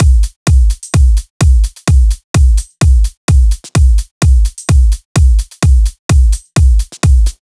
VDE1 128BPM Full Effect Drums 4.wav